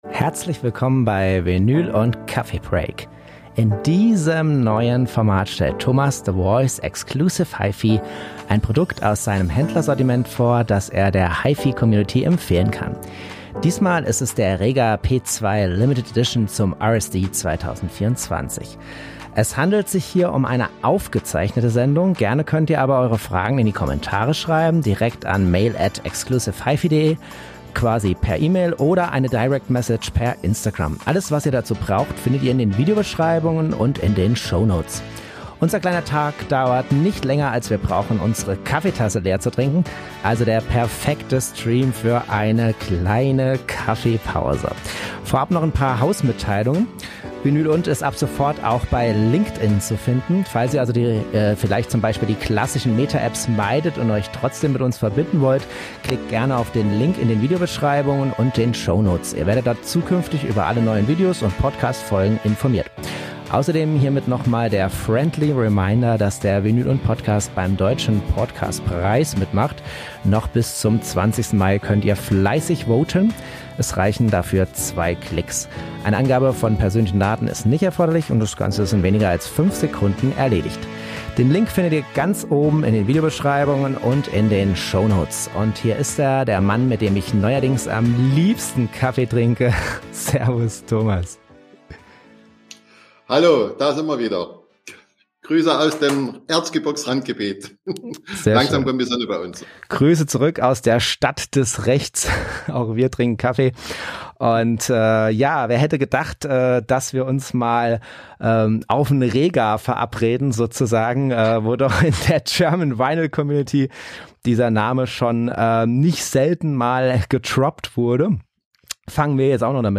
Diesmal ist es der Rega P2 Limited Edition zum RSD 2024. Es handelt sich diesmal um eine aufgezeichnete Sendung.